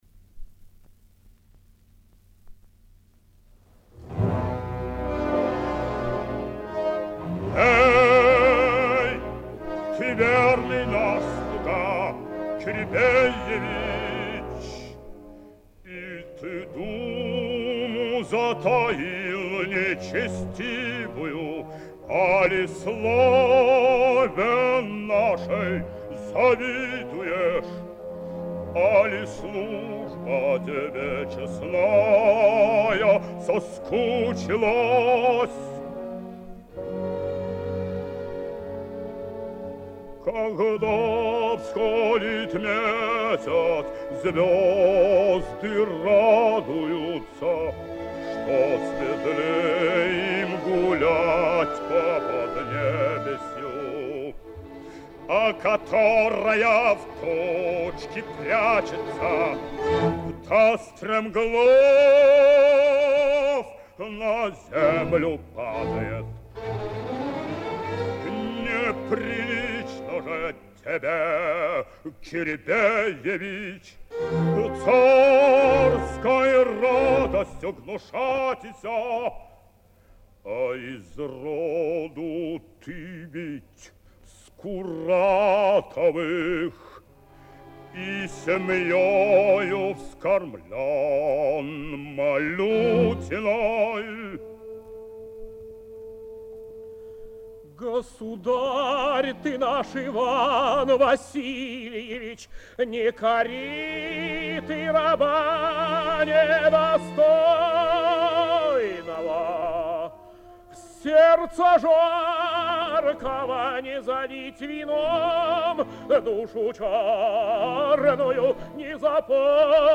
Прослушивание фрагмента аудиозаписи оперы
Рубинштейн_Диалог_Грозного_и_Кирибеевича.mp3